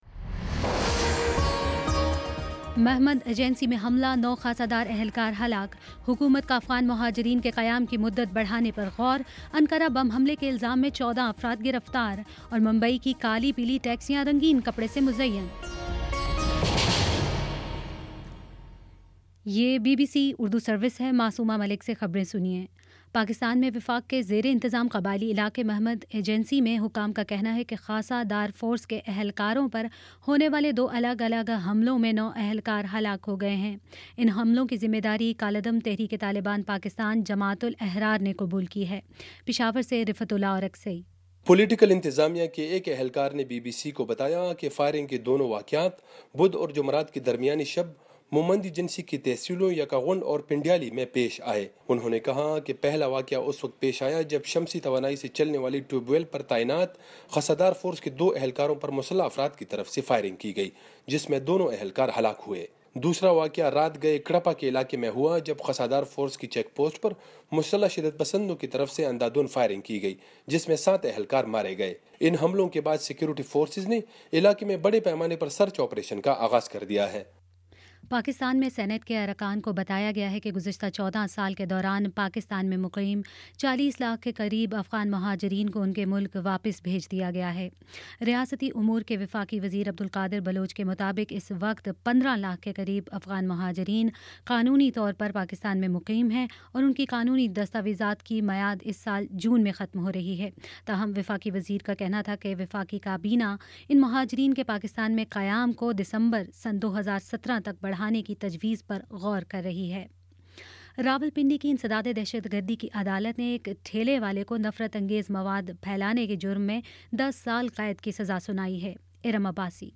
فروری 18 : شام سات بجے کا نیوز بُلیٹن